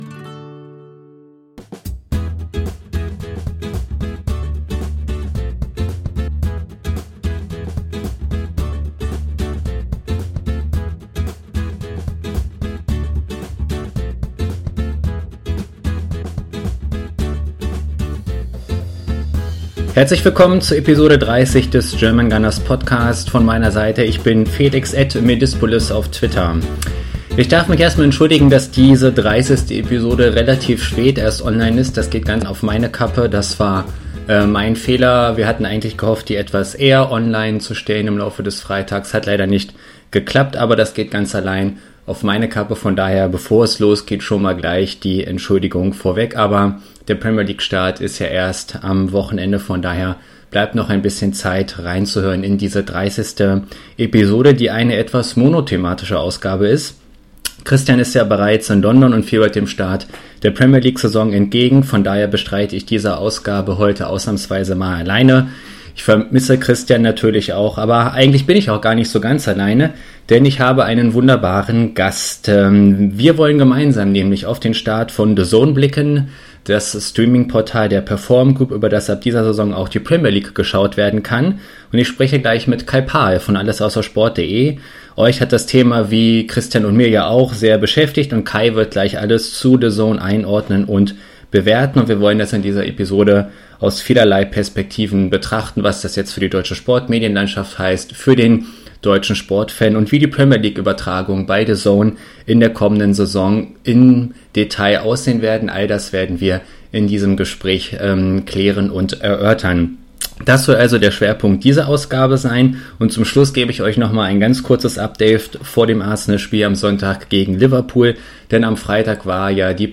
Denn ich habe einen wunderbaren Gast.